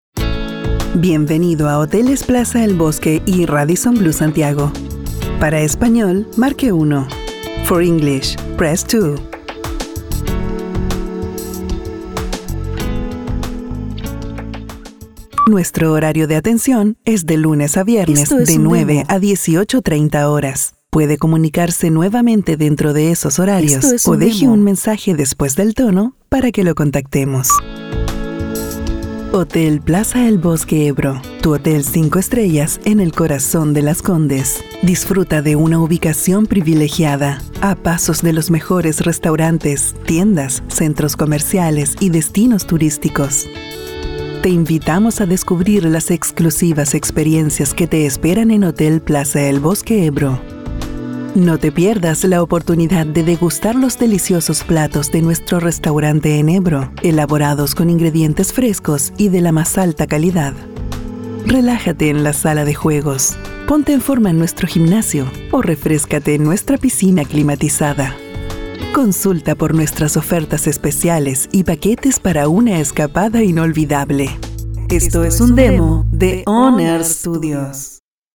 Professionnal, soft, smile, friendly according to the project.
French (Canadian) Adult (30-50)